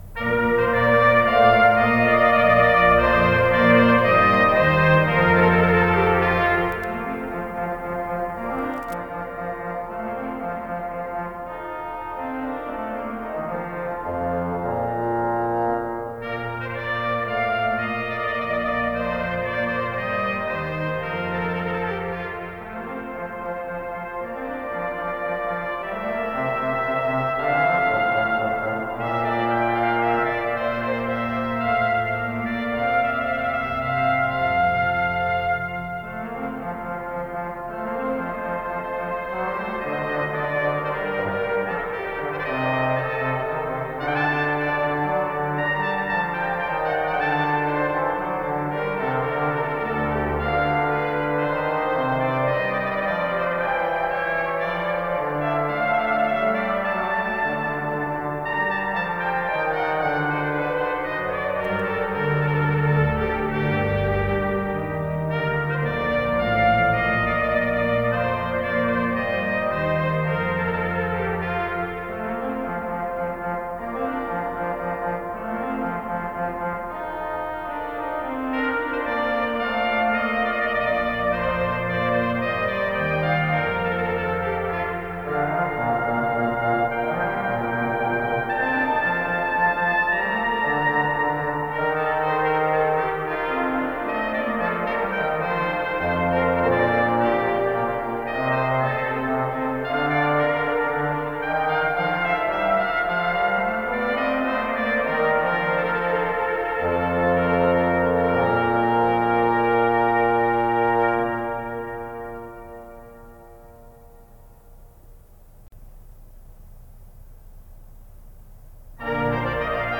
Classical Telemann, Georg Philipp Partita No.1, TWV 41:B1 Oboe version
Oboe  (View more Intermediate Oboe Music)
Classical (View more Classical Oboe Music)